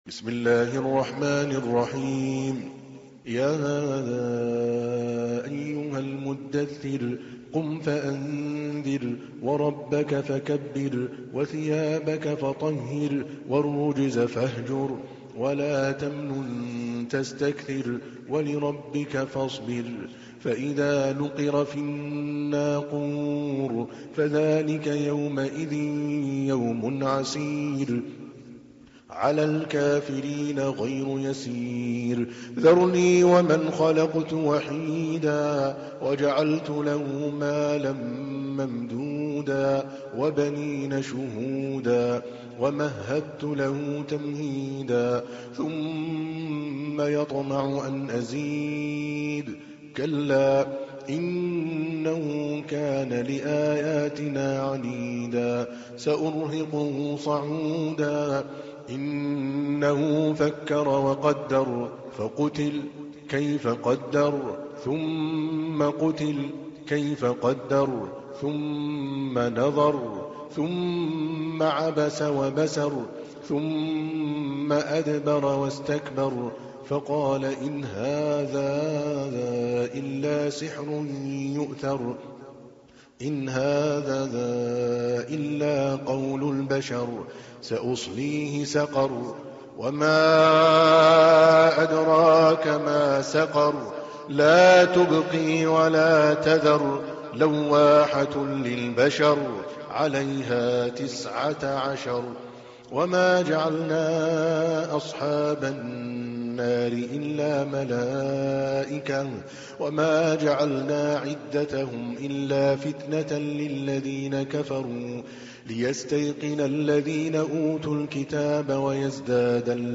تحميل : 74. سورة المدثر / القارئ عادل الكلباني / القرآن الكريم / موقع يا حسين